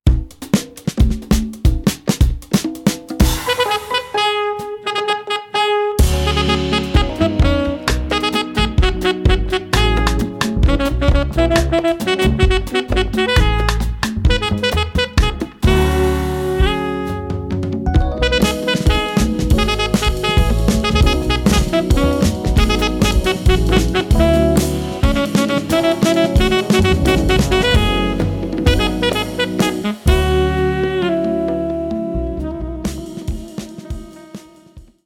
alto flute